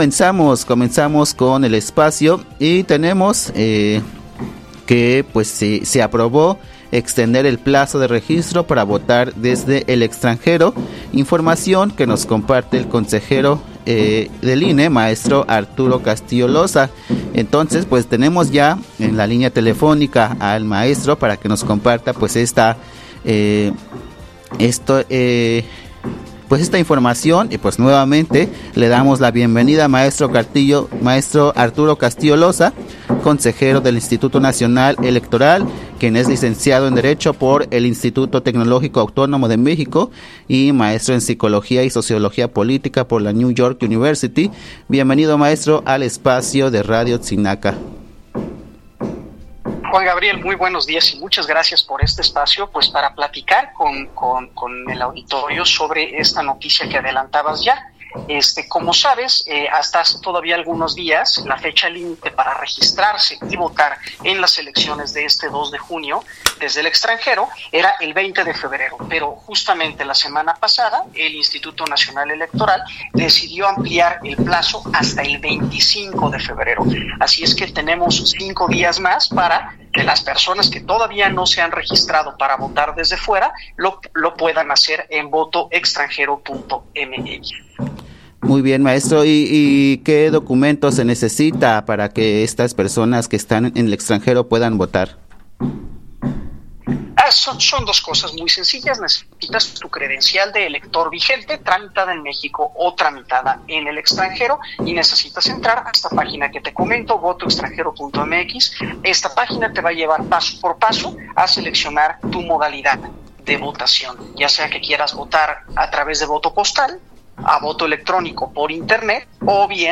Guadalupe Taddei en entrevista de prensa
Entrevista que concedió Guadalupe Taddei, previo a la Sesión Extraordinaria del Consejo General